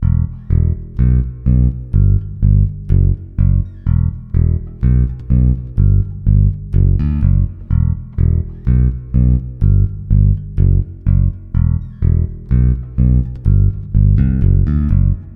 电子低音吉他 125 BPM
Tag: 125 bpm Electro Loops Bass Guitar Loops 2.58 MB wav Key : Unknown